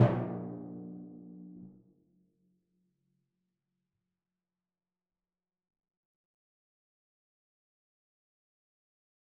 Timpani2_Hit_v4_rr1_Sum.wav